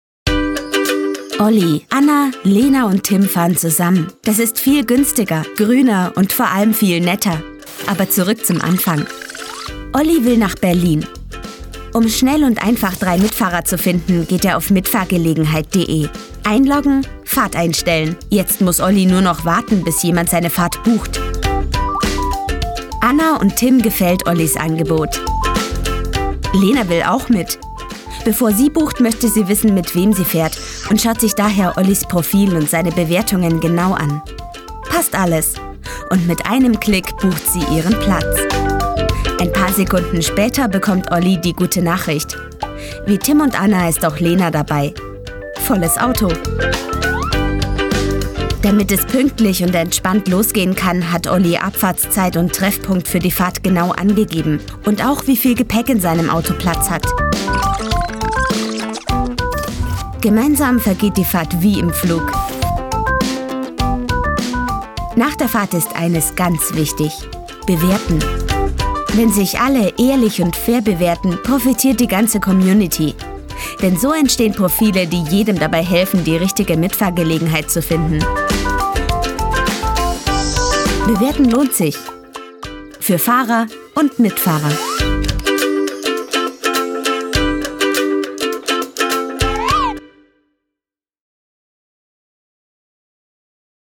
Sprechprobe: Industrie (Muttersprache):
Able to sound very authentic.